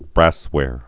(brăswâr)